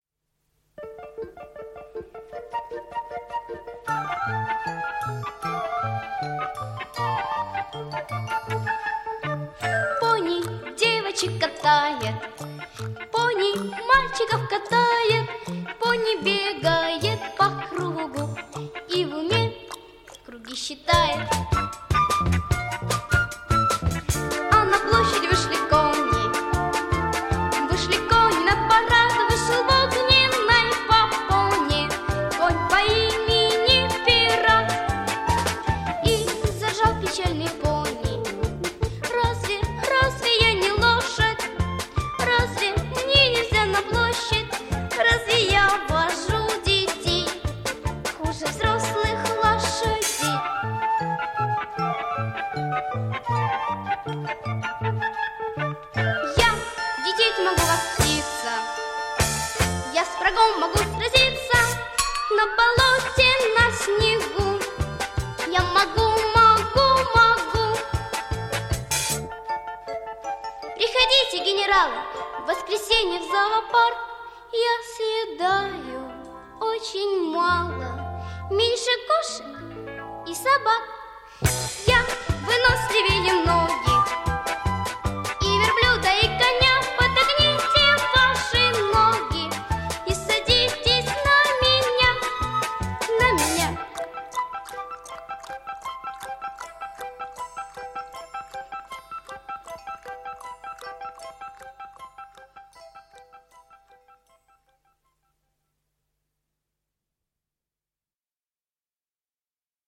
• Категория: Детские песни
советские детские песни